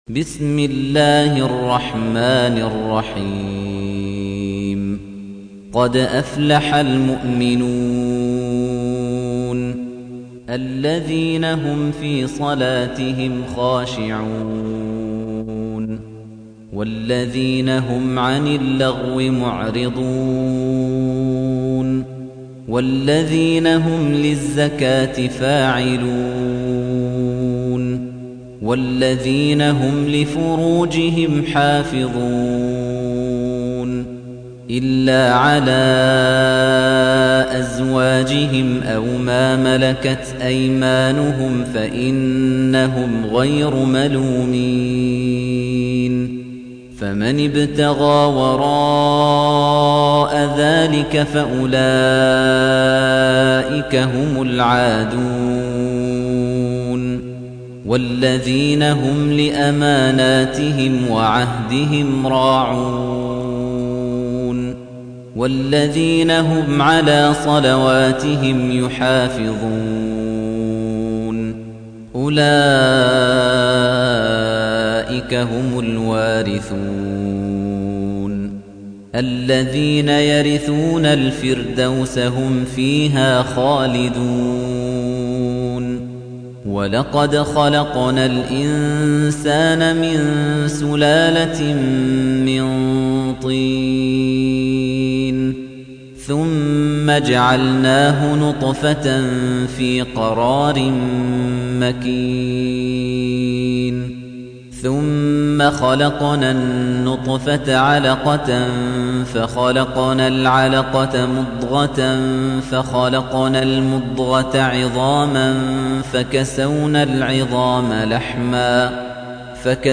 موقع نور القرأن | القران الكريم بصوت القارئ خليفة الطنيجي
فهذا الصوت الإماراتي الرائع في تلاوة القرآن يشتهر بالقوة والوضوح في البيان وسلامة مخارج الألفاظ بشكل ممتاز ، ناهيك على البراعة الخارقة في التجويد والتنويع في التلاوات والخبرة الكبيرة والباع الطويل.